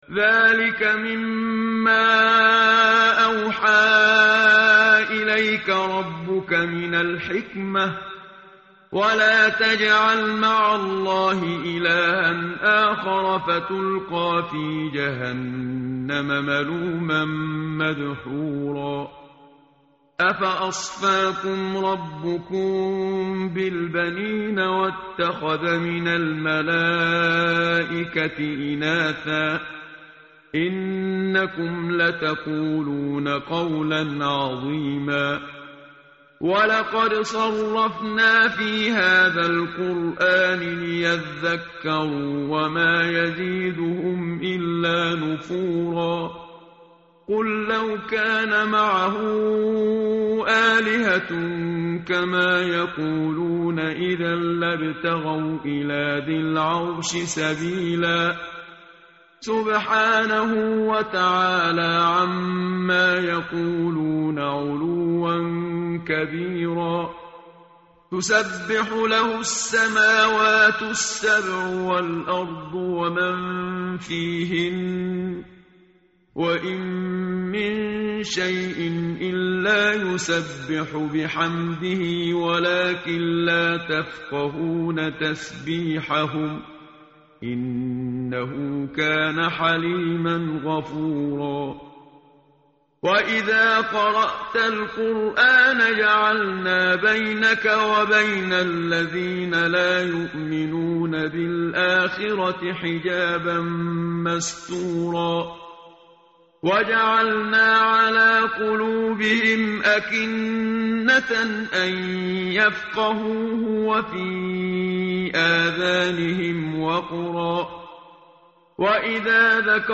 tartil_menshavi_page_286.mp3